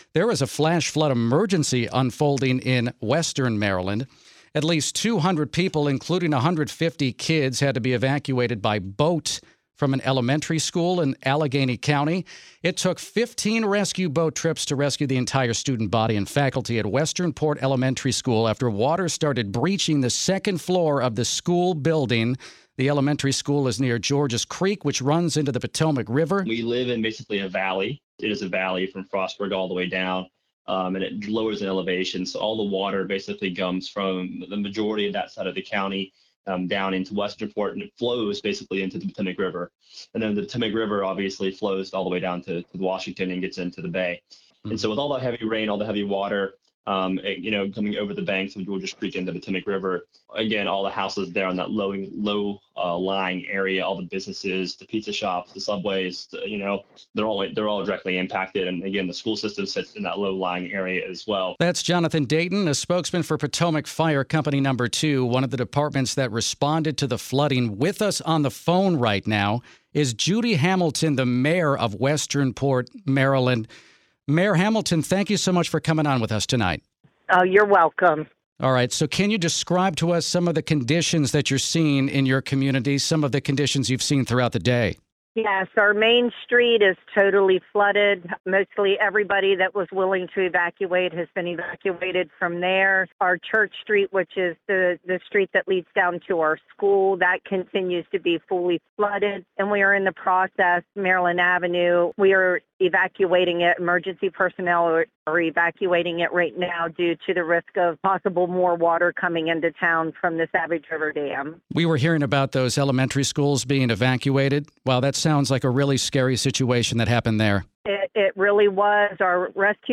Westernport Mayor Judy Hamilton details the flooding conditions in the Maryland town.
Judy-Hamilton-Westernport.mp3